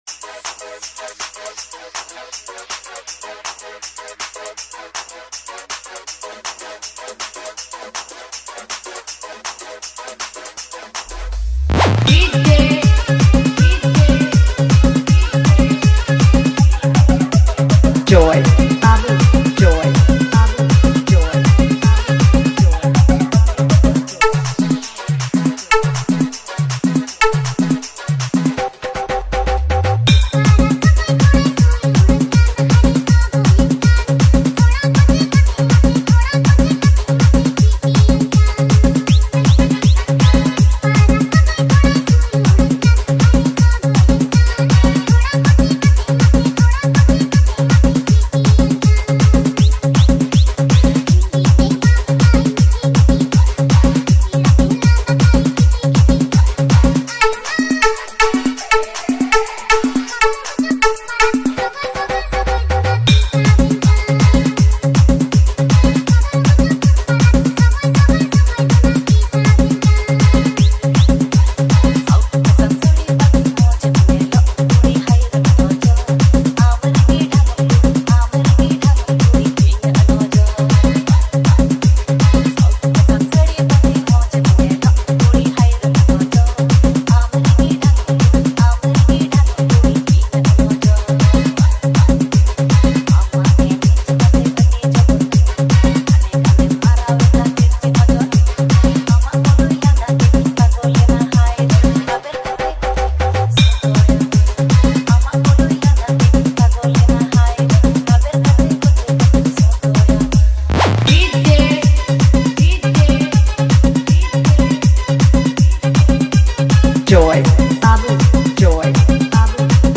Dj Remixer
New Santali Dj Songs